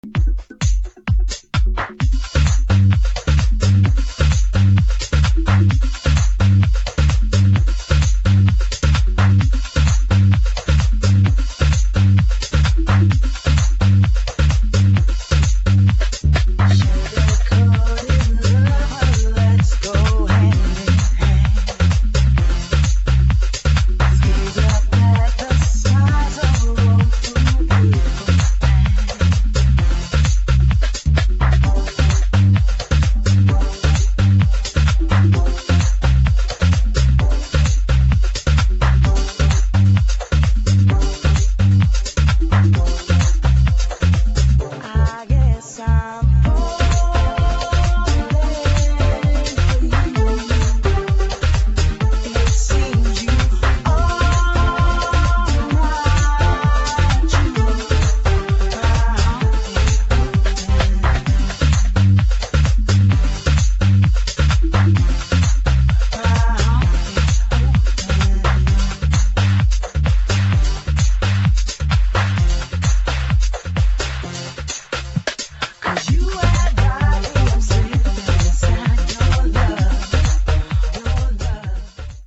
[ DUBSTEP ]